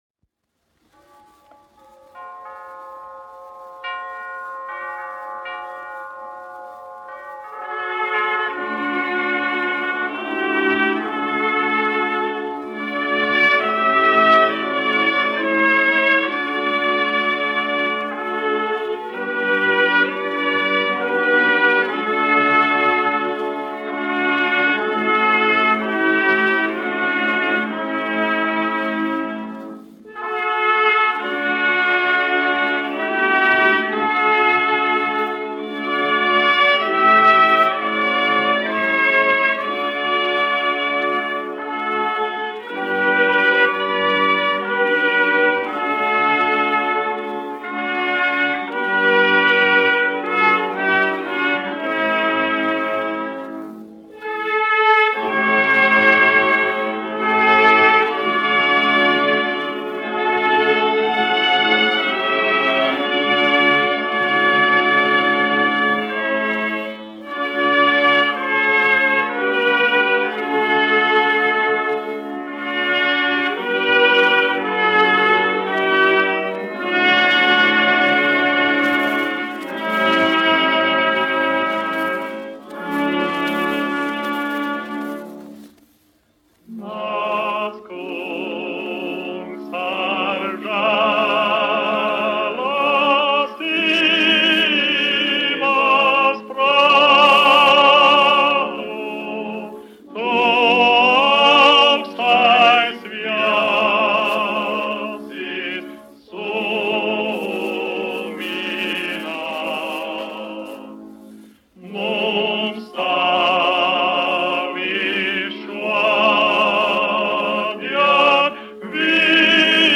1 skpl. : analogs, 78 apgr/min, mono ; 25 cm
Garīgās dziesmas
Latvijas vēsturiskie šellaka skaņuplašu ieraksti (Kolekcija)